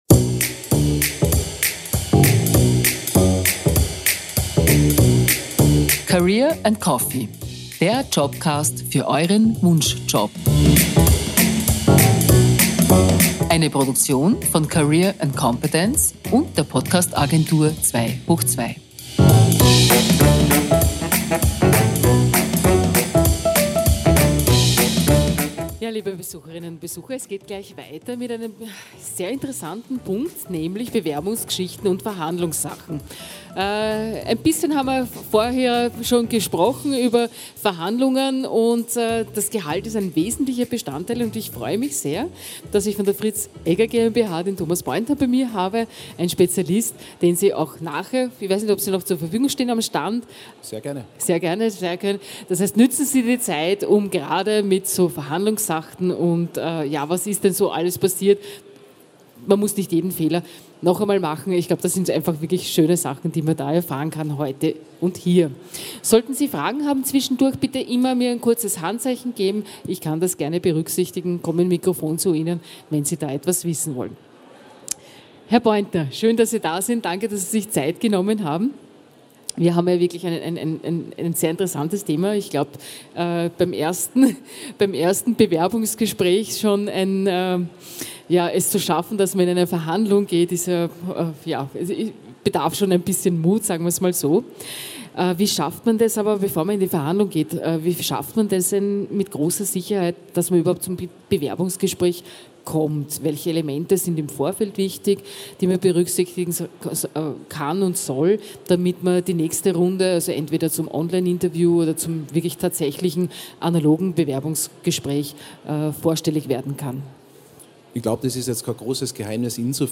Livemitschnitt von der career & competence 2023 in Innsbruck, am 26. April 2023.